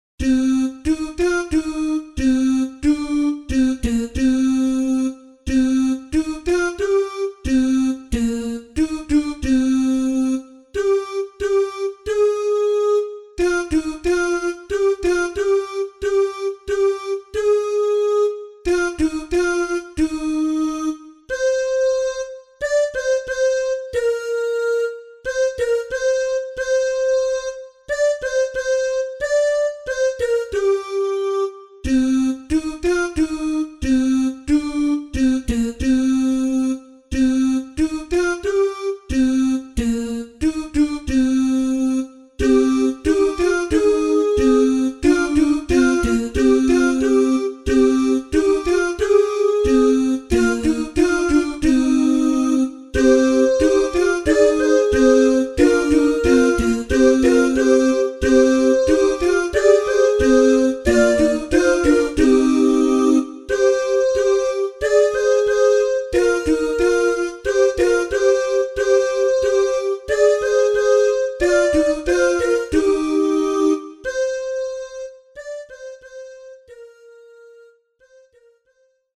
CANONS